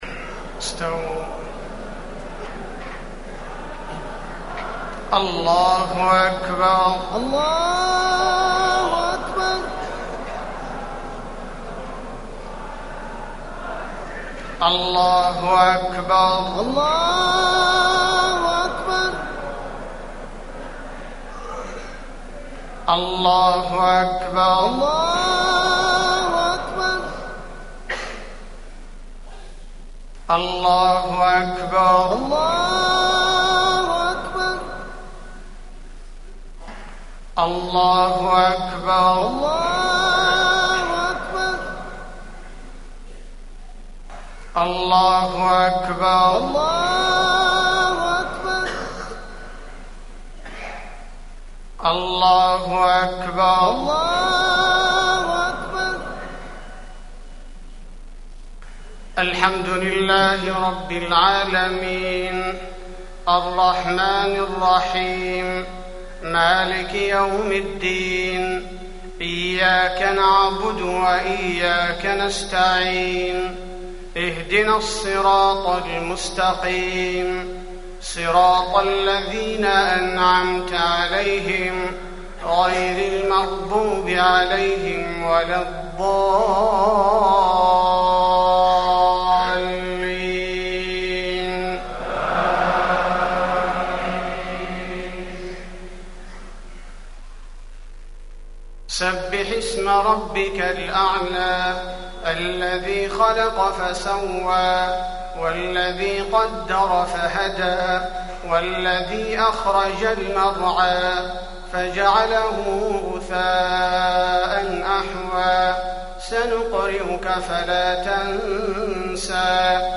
خطبة عيد الفطر- المدينة - الشيخ عبدالباريءالثبيتي
تاريخ النشر ١ شوال ١٤٢٧ هـ المكان: المسجد النبوي الشيخ: فضيلة الشيخ عبدالباري الثبيتي فضيلة الشيخ عبدالباري الثبيتي خطبة عيد الفطر- المدينة - الشيخ عبدالباريءالثبيتي